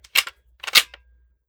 7Mag Bolt Action Rifle - Slide Up-Back 002.wav